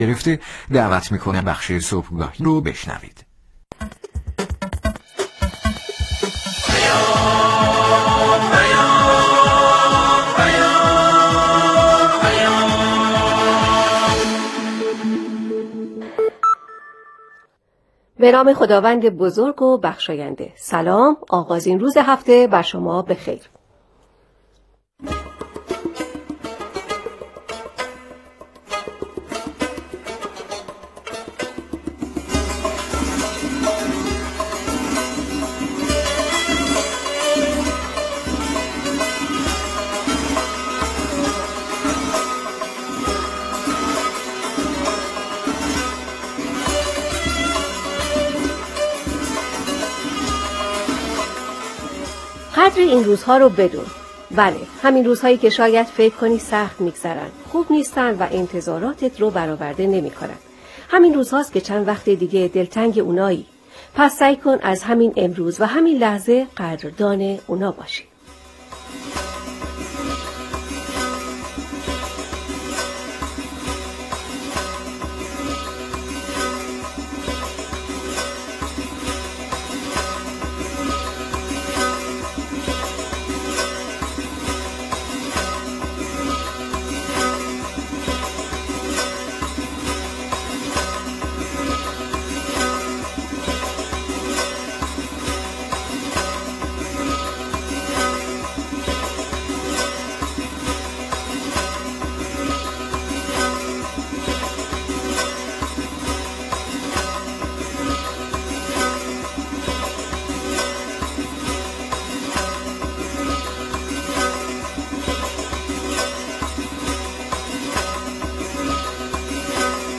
مصاحبه
در گفتگو و ارتباط زنده با رادیو پیام صدای مرکز بوشهر